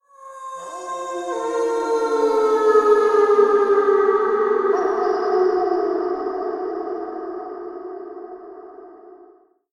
Download Wild Animals sound effect for free.
Wild Animals